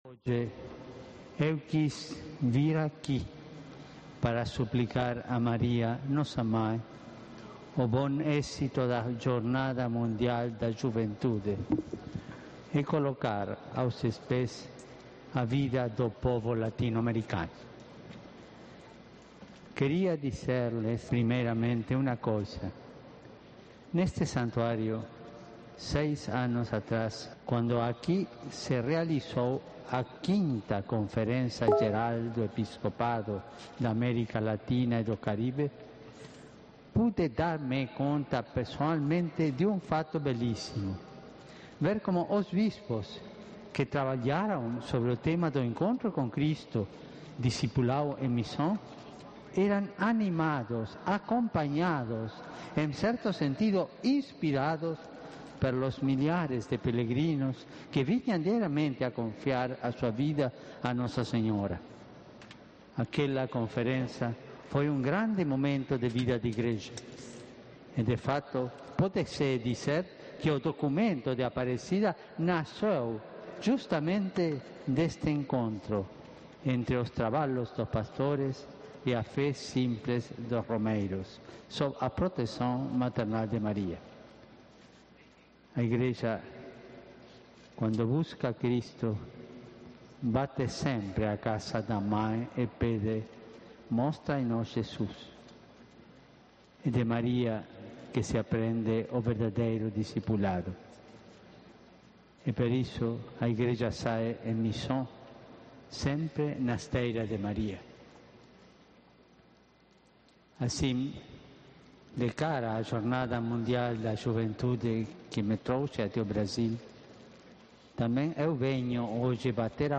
Escucha la Homilía del Papa Francisco en el Santuario de Aparecida